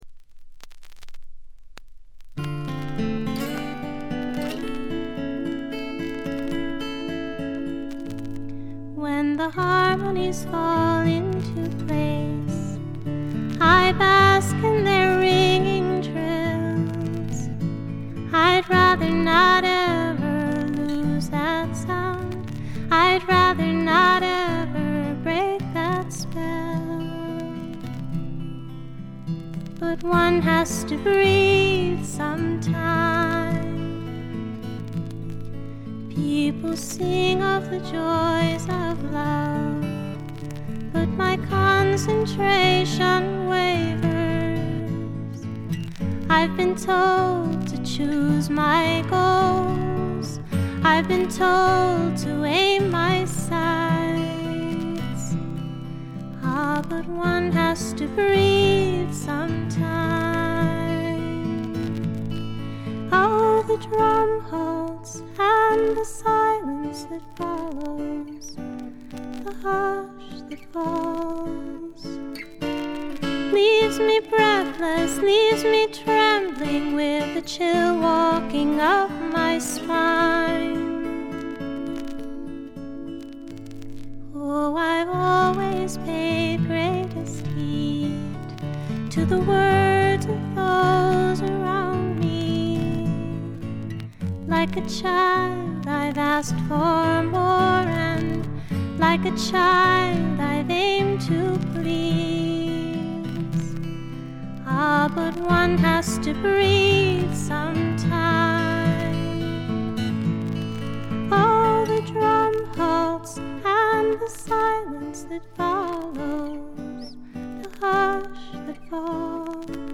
バックグラウンドノイズ、チリプチ多め大きめです（特にA1は目立ちます）。
内容は自身の弾き語りを中心にした静謐でピュアで美しいフォーク・アルバムです。
試聴曲は現品からの取り込み音源です。
Vocals, Guita, Psalteryr